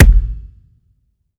normal-hitnormal.wav